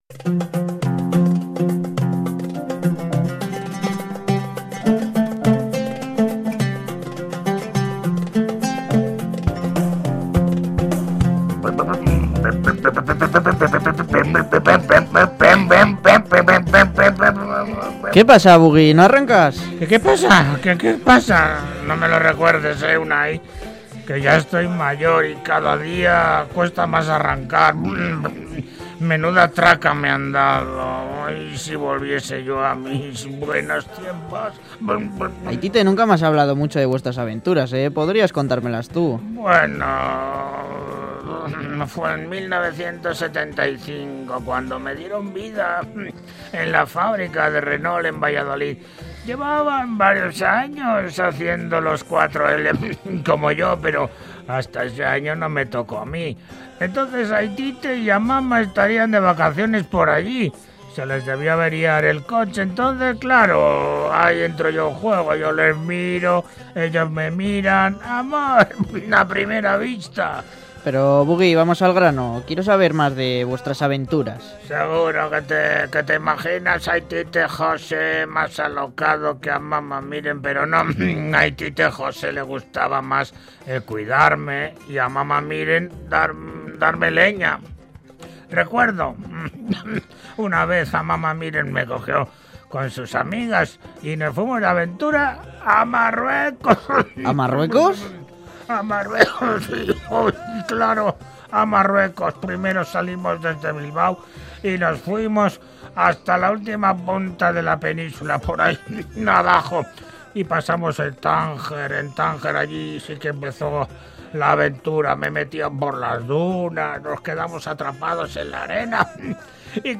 Audio: Reportaje: UniRaid! El Raid solidario para estudiantes.